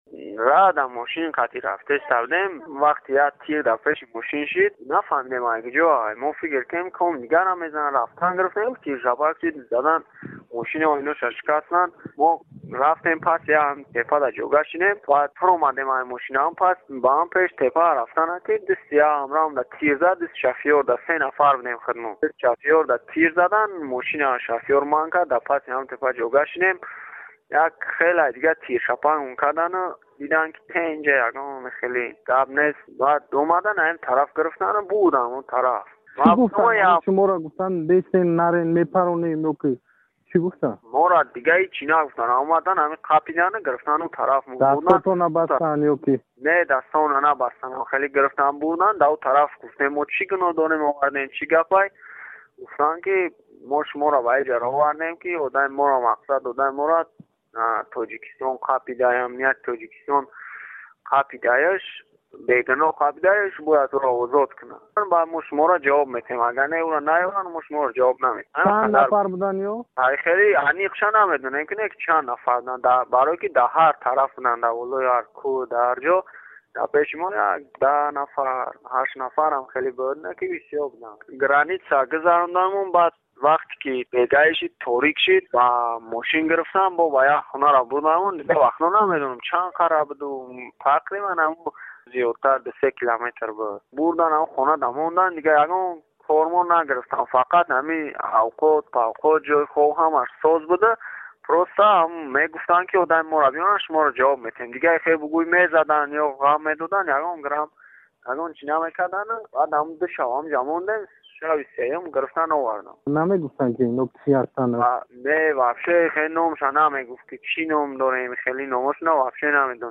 дар сӯҳбат бо Озодӣ ҷараёни ба асорат афтодан ва